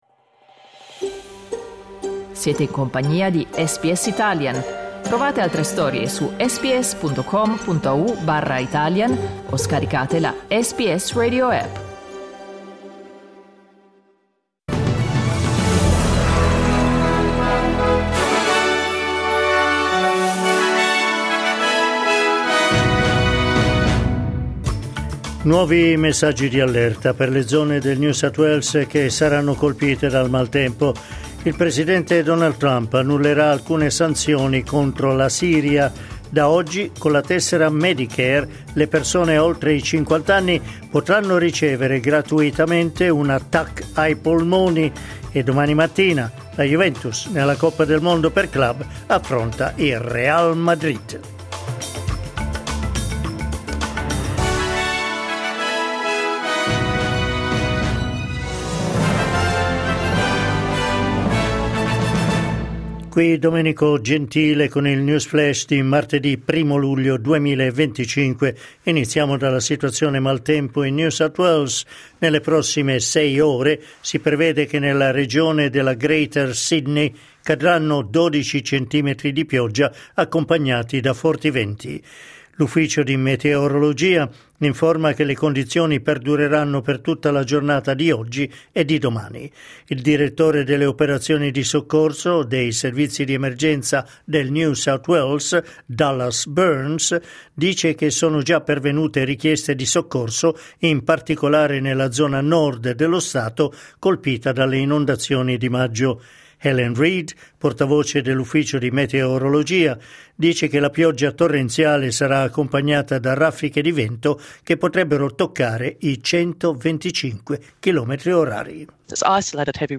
News flash martedì 1 luglio 2025
L’aggiornamento delle notizie di SBS Italian.